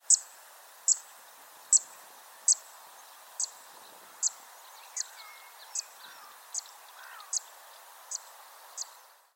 XC713317-fuinha-dos-juncos-Cisticola-juncidis